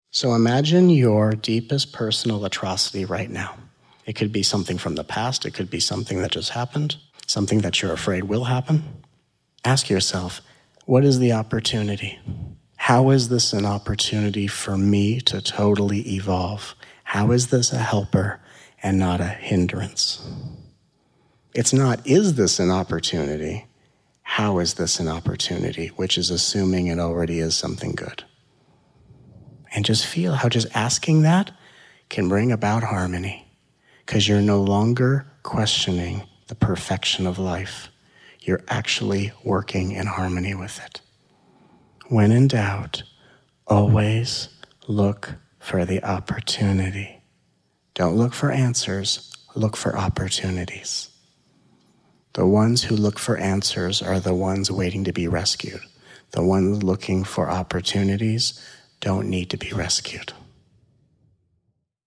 It is a collection of hits and highlights from the November 2015 Grass Valley, CA weekend immersion.